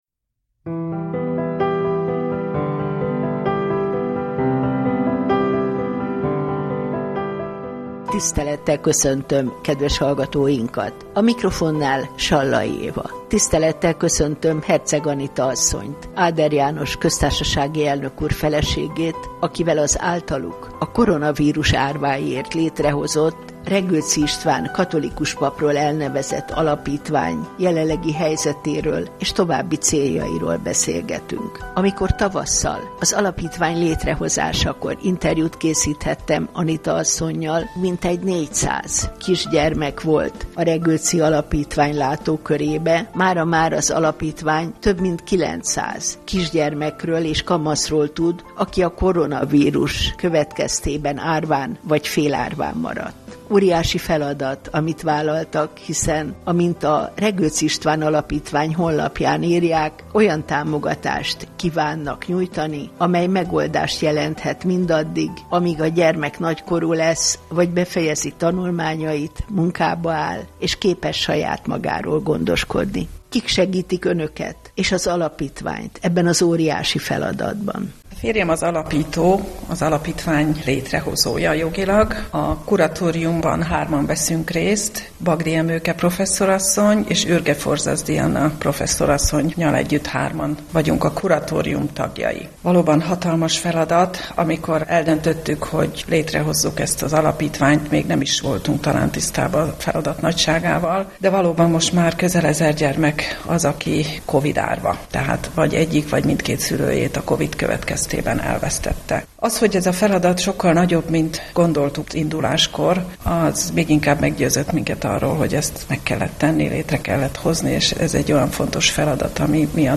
Beszélgetés a Regőczi István Alapítvány tevékenységéről a Katolikus Rádió Kerengő című műsorában